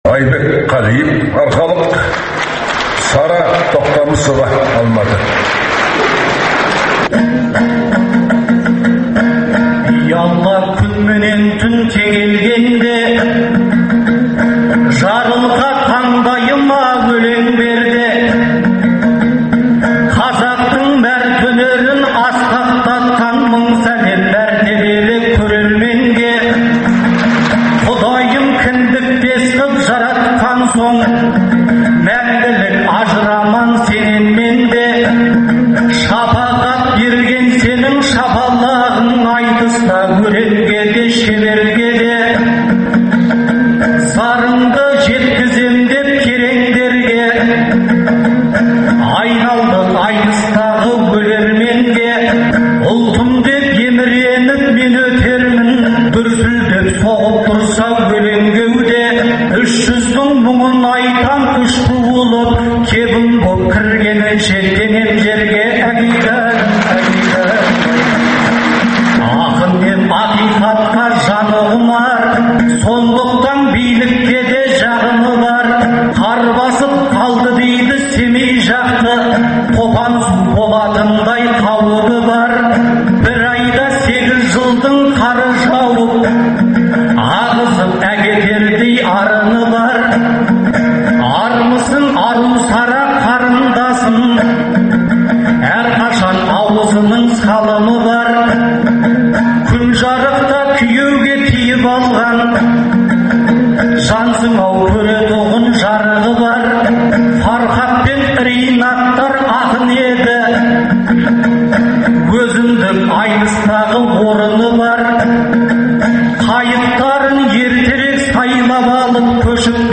Айтыстан үзінділер беріледі, ақындар айтысының үздік нұсқалары тыңдарменға сол қалпында ұсынылып отырады.